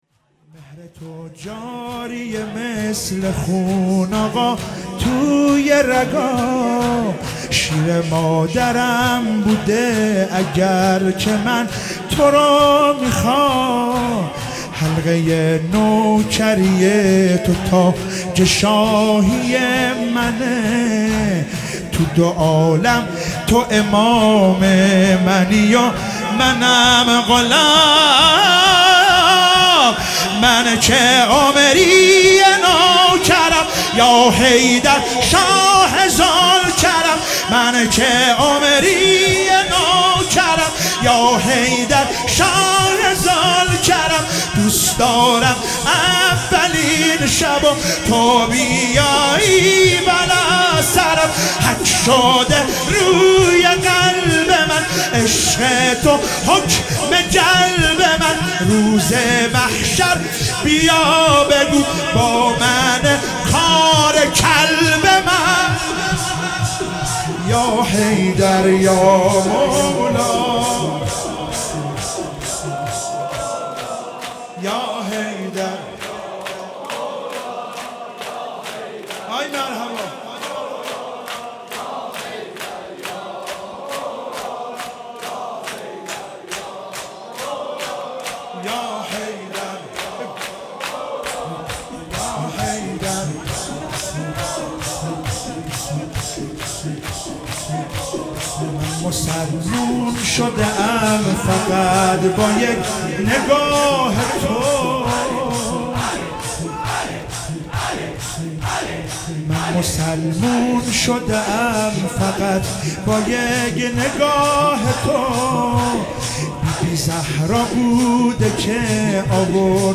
محرم96